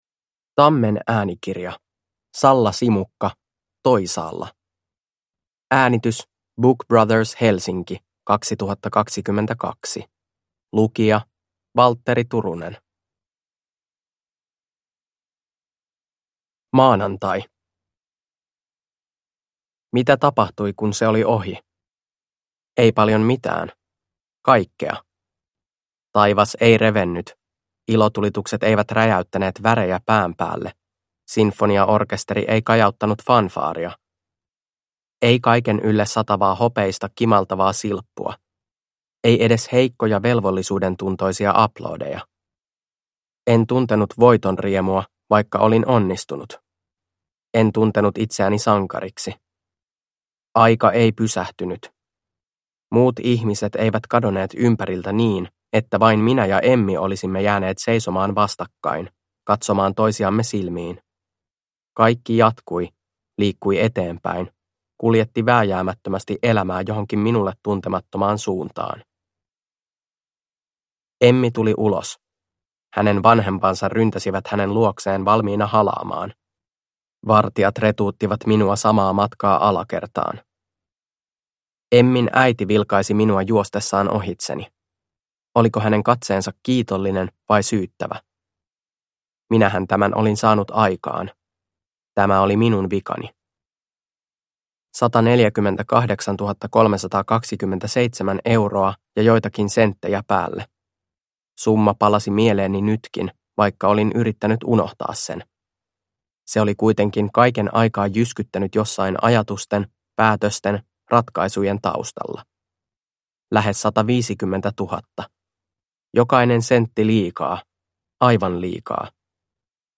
Toisaalla – Ljudbok – Laddas ner